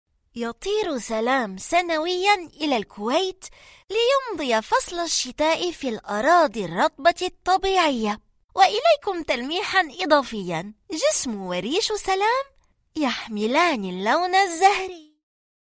VoiceBros International Media - Professional Online Voice-over Portal
Female